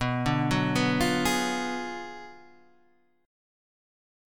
B Minor 6th Sharp 11th
Bm6+11 chord {7 5 4 4 6 4} chord